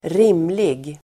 Uttal: [²r'im:lig]